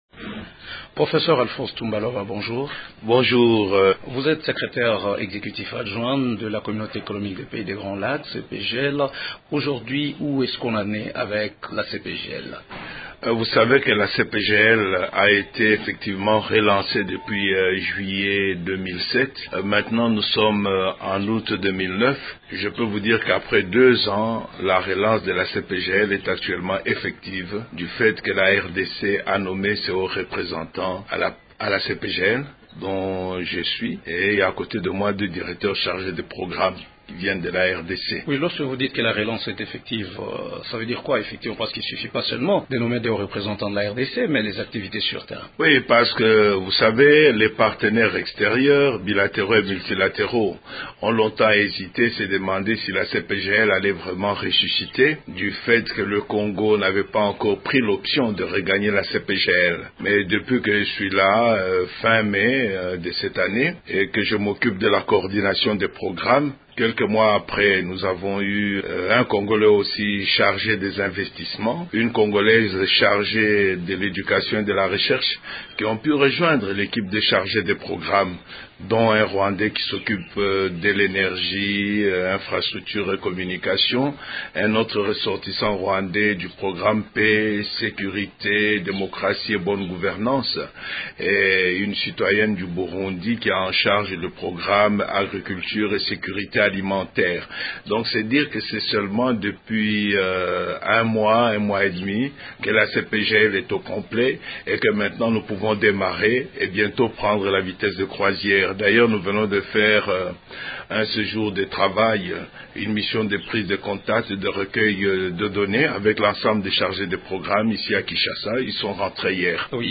Nous parlons des relations entre la RDC et ses voisins rwandais et burundais au sein de la CEPGL avec notre invité du jour, le professeur Alphonse Ntumba Luaba.